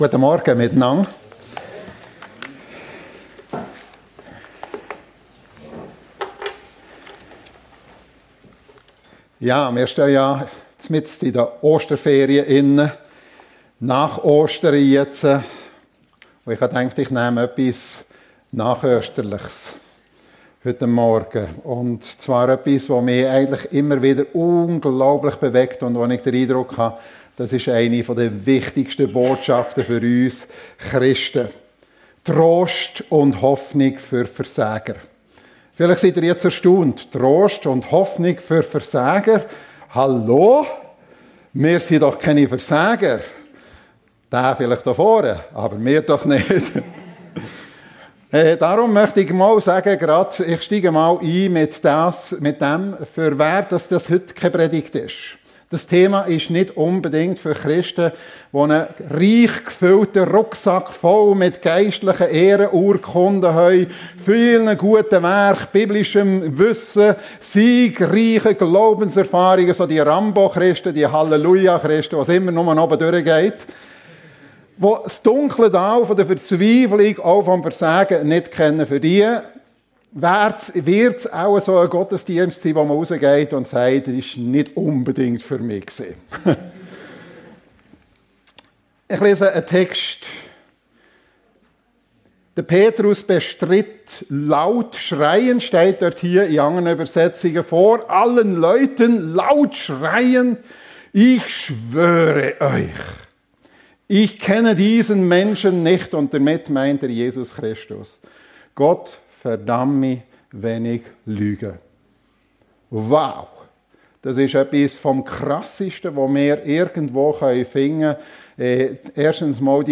Trost und Hoffnung für Versager ~ FEG Sumiswald - Predigten Podcast